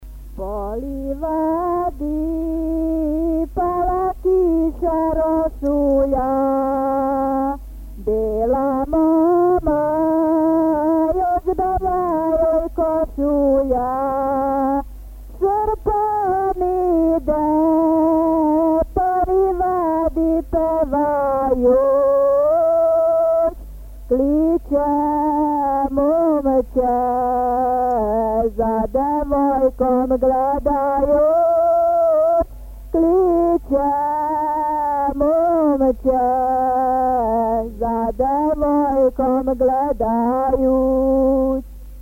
Városi énekek
Megjegyzés: Az előző (119) dal variánsa, másfajta manírral, hangszínnel és más esztétikai elvárásokkal.